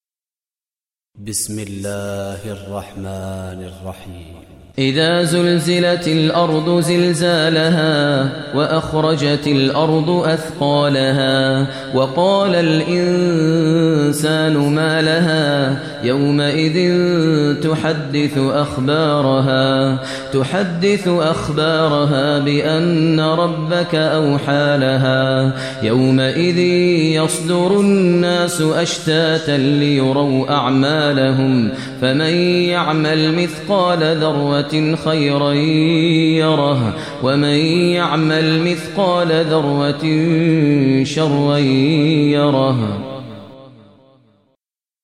Surah Zalzalah Recitation by Maher al Mueaqly
Surah Zalzalah is 99 chapter of Holy Quran. Listen online mp3 tilawat / recitation in Arabic in the voice of Sheikh Maher al Mueaqly.